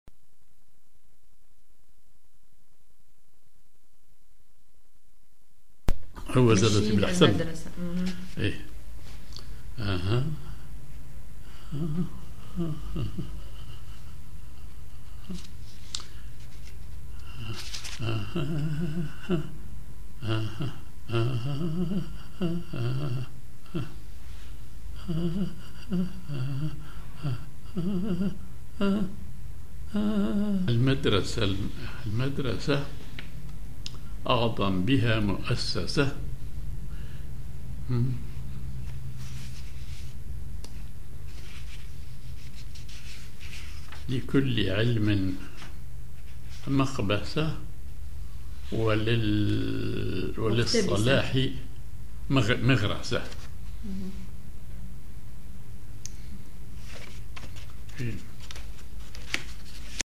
Maqam ar راست على درجة النوا
genre نشيد